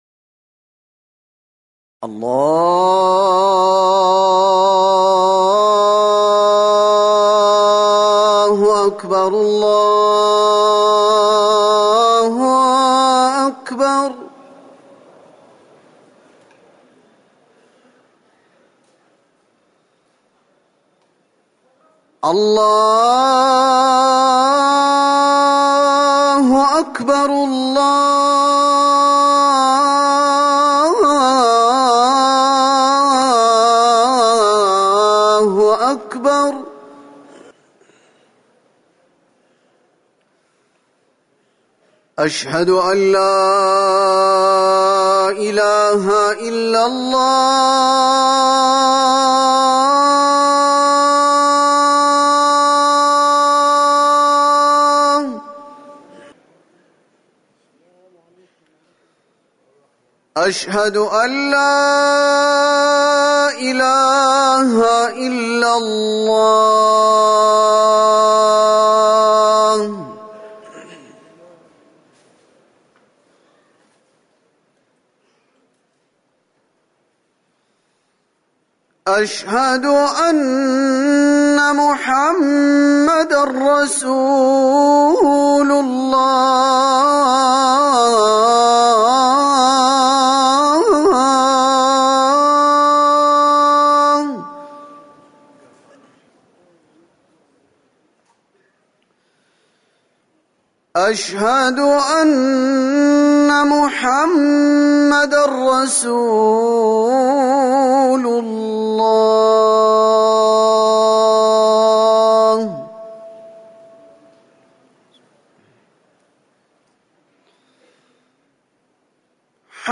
أذان المغرب - الموقع الرسمي لرئاسة الشؤون الدينية بالمسجد النبوي والمسجد الحرام
تاريخ النشر ١٨ صفر ١٤٤١ هـ المكان: المسجد النبوي الشيخ